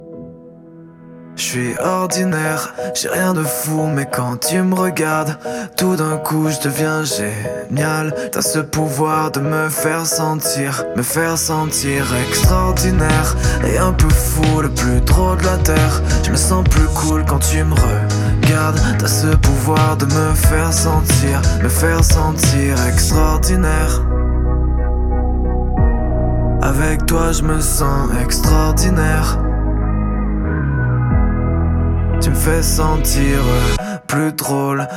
French Pop
Жанр: Поп музыка